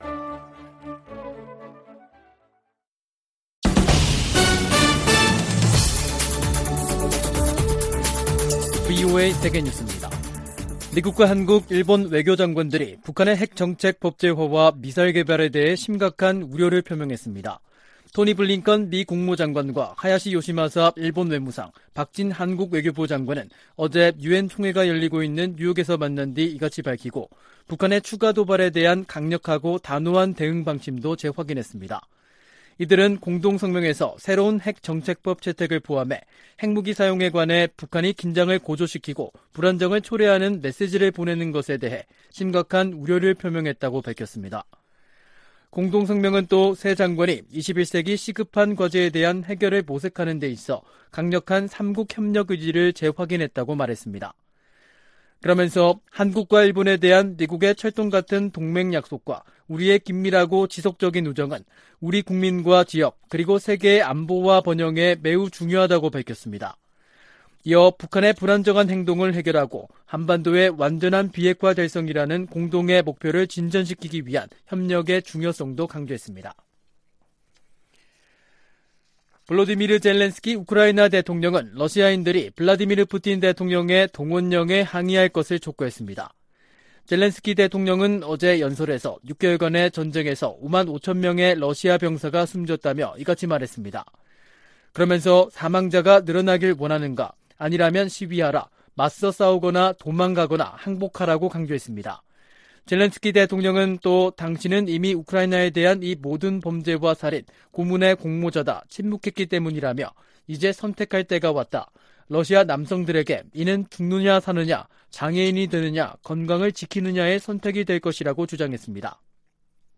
VOA 한국어 간판 뉴스 프로그램 '뉴스 투데이', 2022년 9월 23일 3부 방송입니다. 미 핵 추진 항공모함 로널드 레이건이 연합 훈련을 위해 부산에 입항했습니다. 미한일 외교장관들이 뉴욕에서 회담하고 북한의 핵 정책 법제화에 심각한 우려를 표시했습니다. 제이크 설리번 미국 국가안보보좌관은 북한의 7차 핵 실험 가능성이 여전하다고 밝혔습니다.